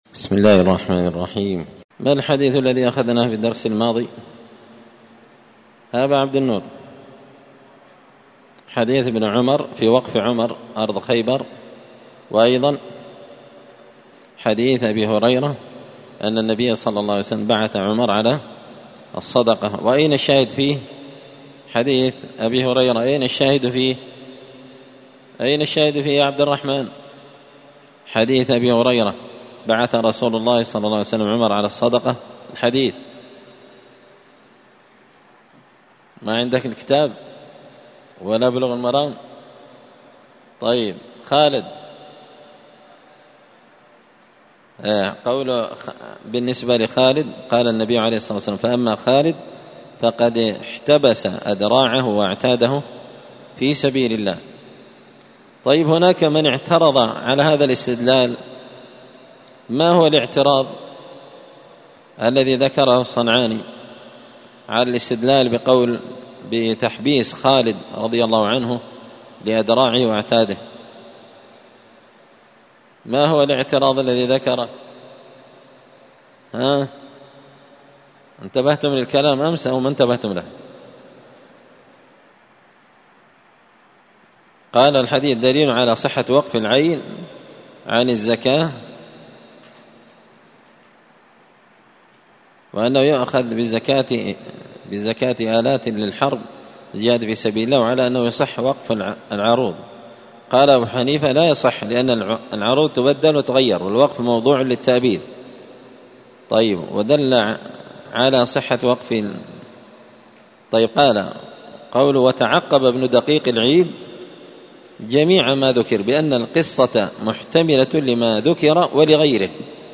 كتاب البيوع من سبل السلام شرح بلوغ المرام لابن الأمير الصنعاني رحمه الله تعالى الدرس – 128